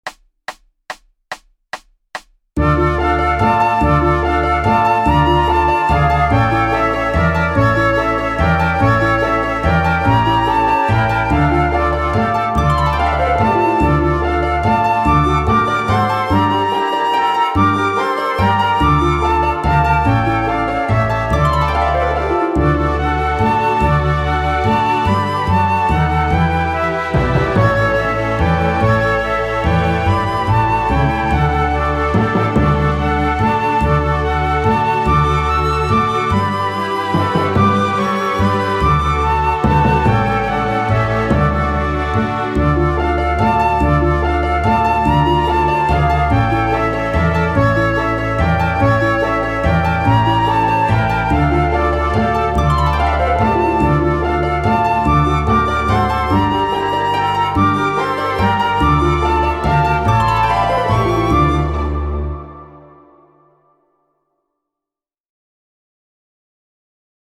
3 flutes
• 3 dwarsfluiten met meespeel CD
Met meespeel CD met orkest.